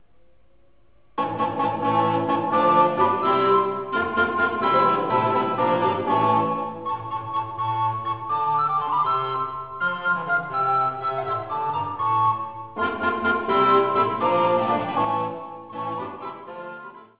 LA MUSICA DA BALLO
IL RICERCARE
Gruppo di Strumenti Antichi